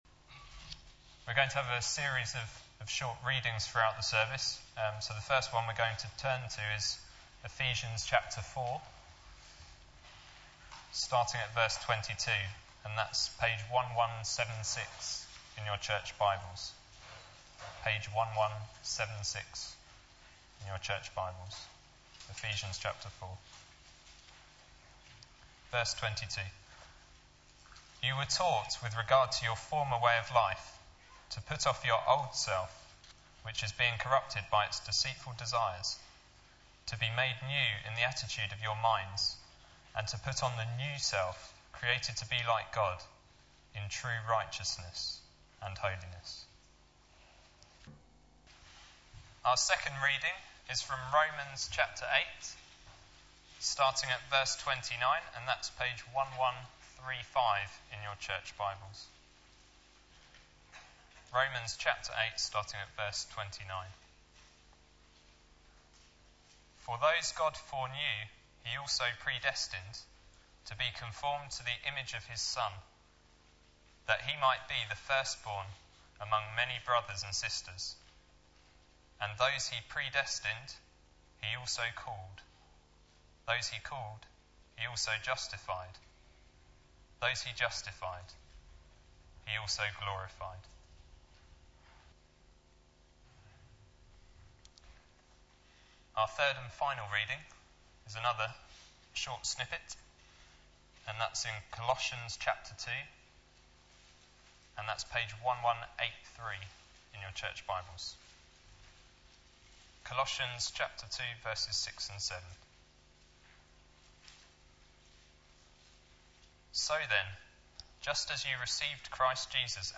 Media for Sunday Service
Sermon